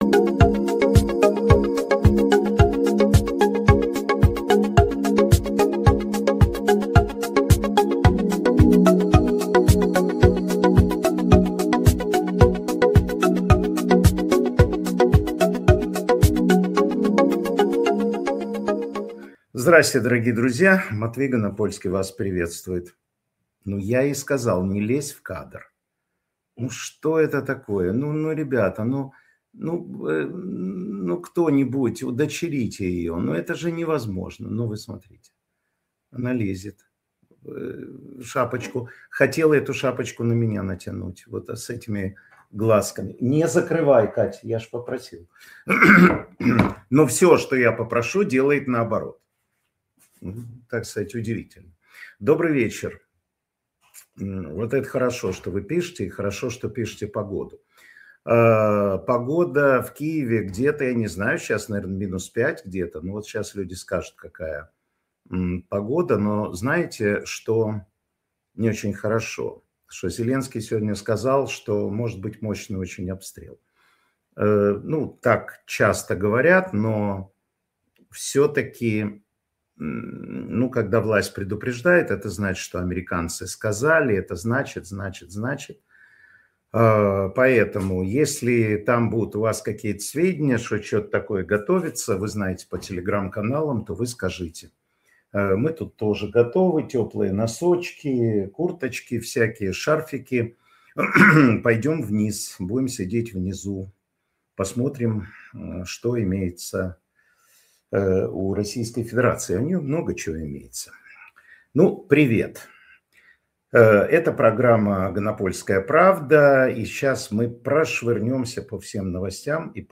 Информационно-аналитическая программа Матвея Ганапольского